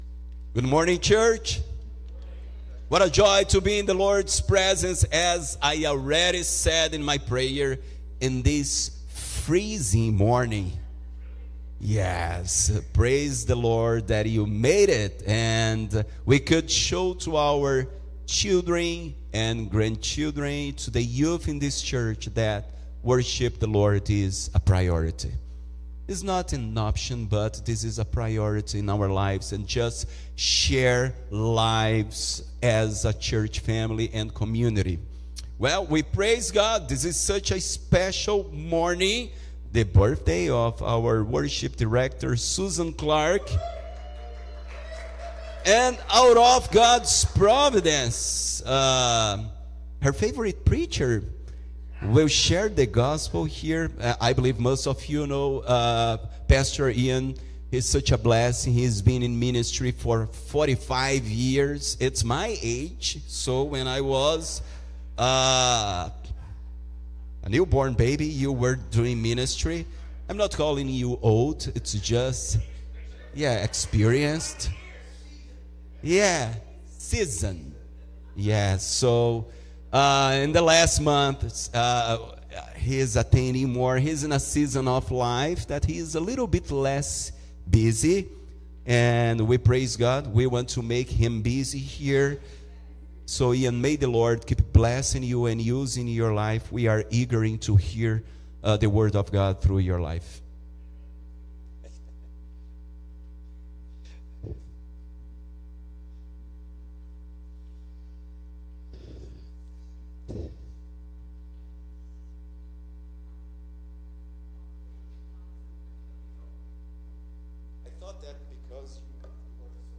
Sermons from Huntingdon Valley Presbyterian Church